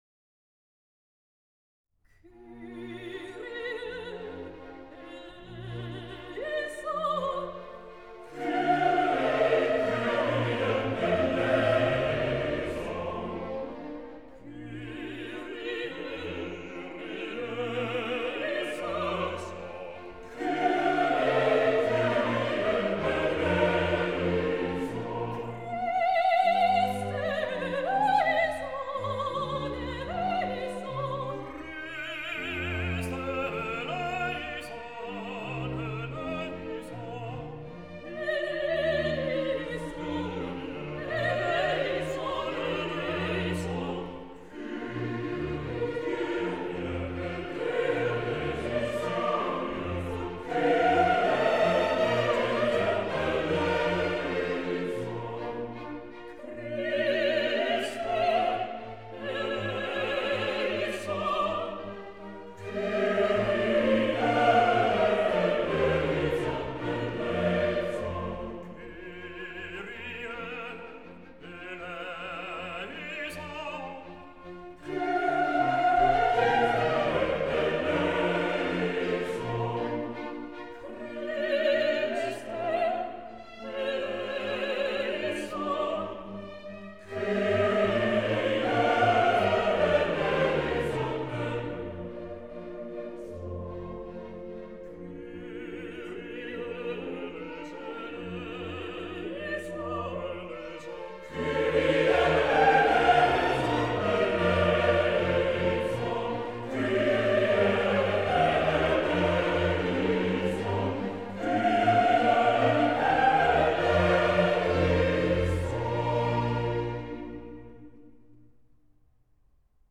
» nhac-khong-loi